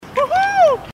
Hoo Hoo Echo